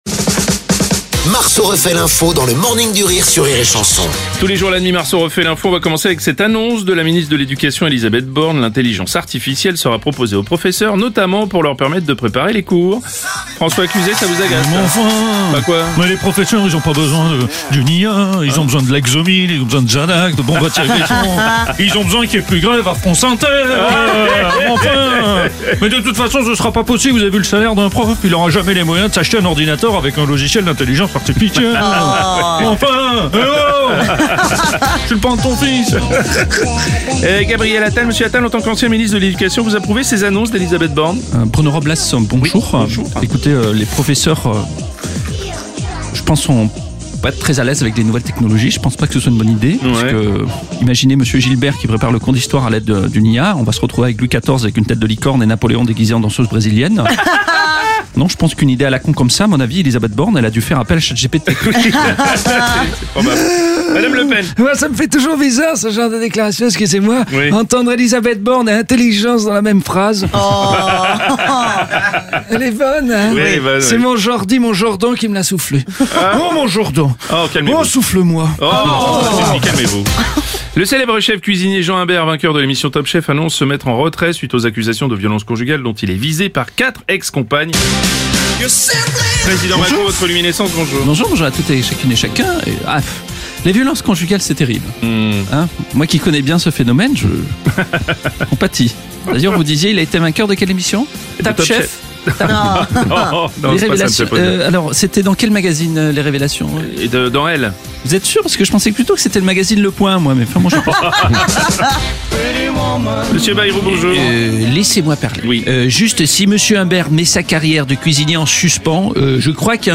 débriefe l’actu en direct à 7h30, 8h30, et 9h30.